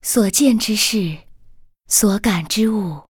文件 文件历史 文件用途 全域文件用途 Dana_amb_02.ogg （Ogg Vorbis声音文件，长度3.1秒，99 kbps，文件大小：37 KB） 源地址:游戏语音 文件历史 点击某个日期/时间查看对应时刻的文件。 日期/时间 缩略图 大小 用户 备注 当前 2018年4月20日 (五) 02:44 3.1秒 （37 KB） 地下城与勇士  （ 留言 | 贡献 ） 源地址:游戏语音 您不可以覆盖此文件。